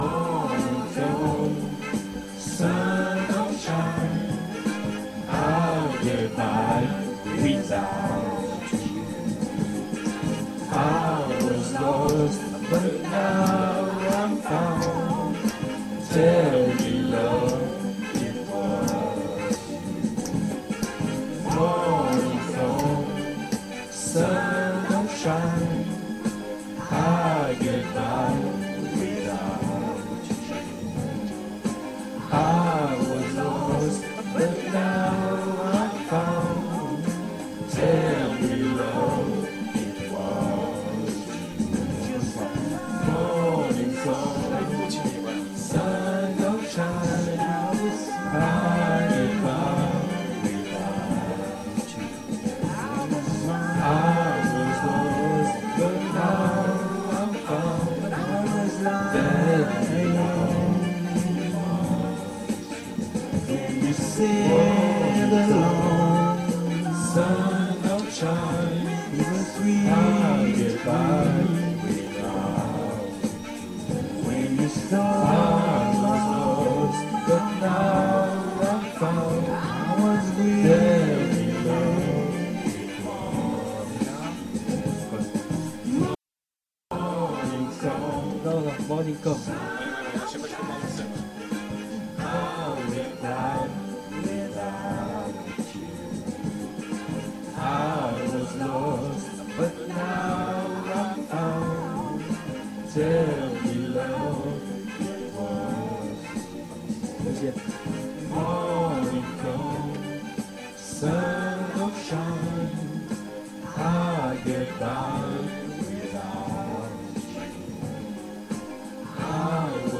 Nos choeurs... pour travail
choeurs_IGotBlues.mp3